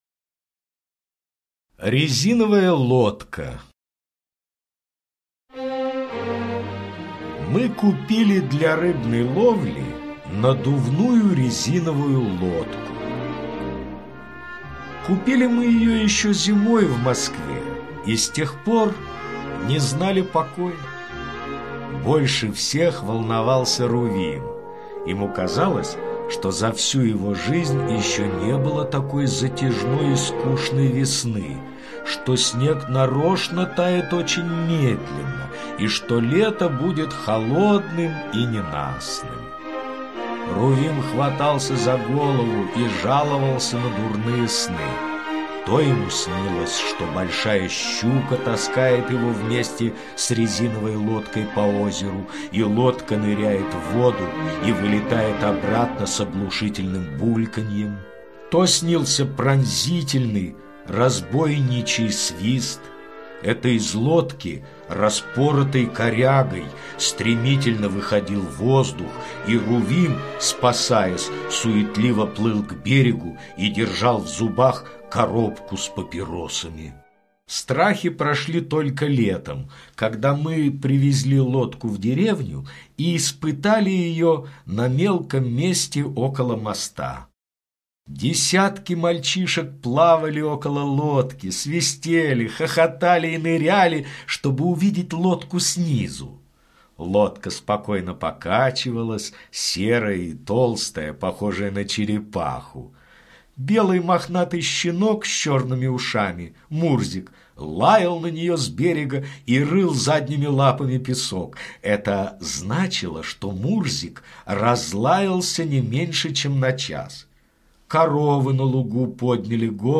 Резиновая лодка - аудио рассказ Паустовского К. Автор рассказа и Рувим купили резиновую лодку для рыбалки и с нетерпением ждали лета.